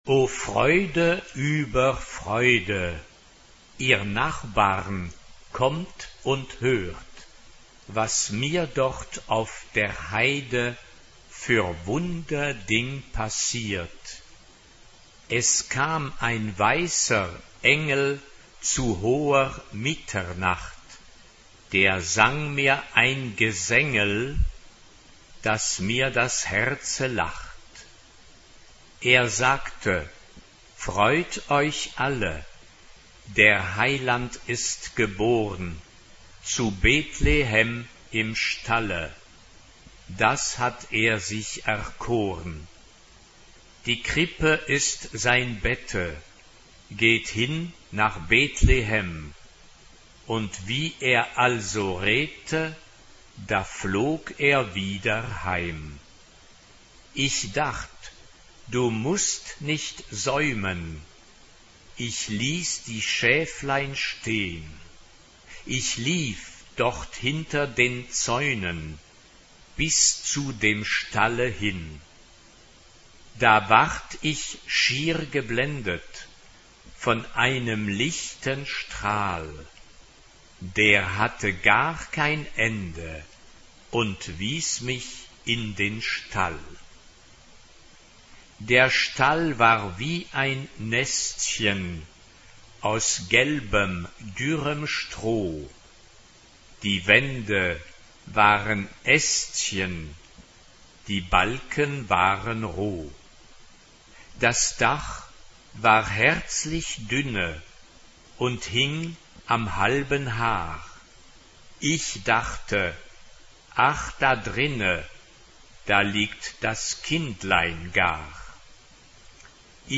SSA (3 voces Coro femenino) ; Partitura general.
Canción de Navidad.
Sagrado ; Cántico ; Canción de Navidad
Tonalidad : fa mayor